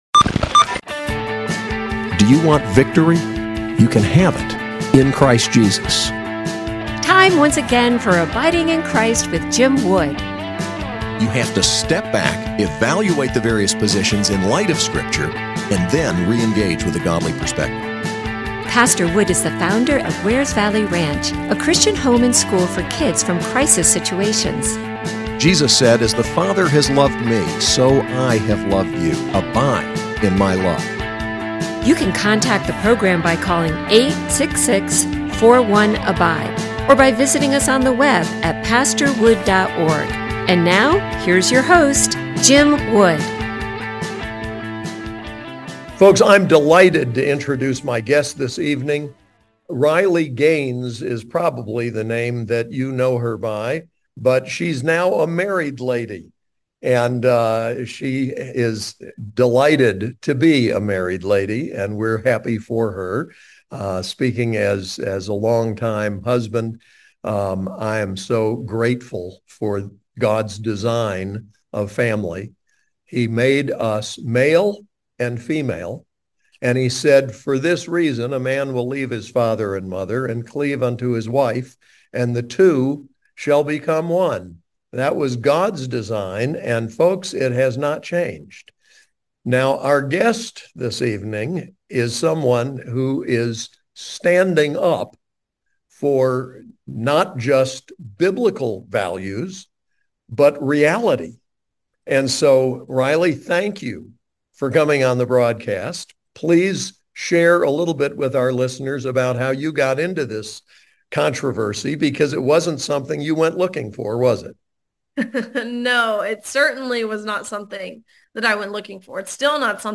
Interview with Riley Gaines